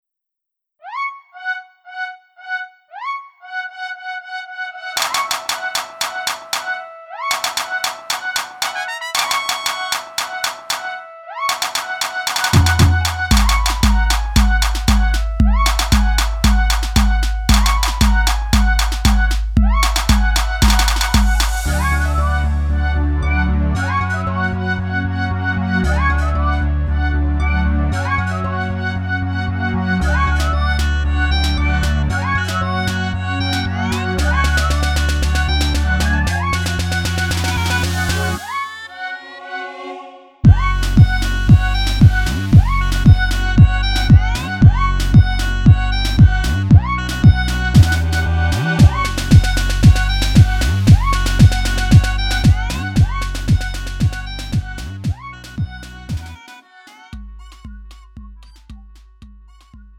음정 원키
장르 구분 Lite MR